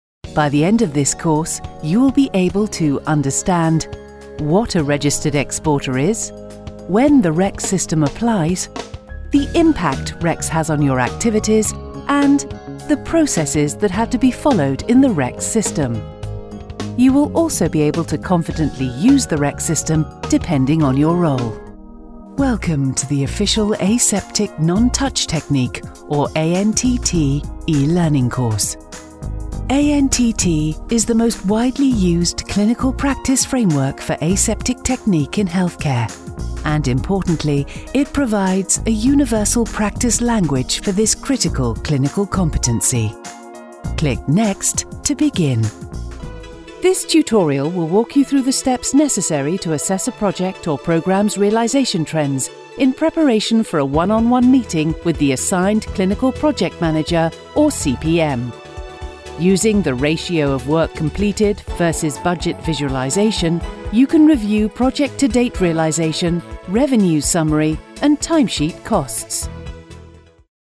UK British Female Voiceover
ELEARNING